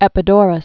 (ĕpĭ-dôrəs)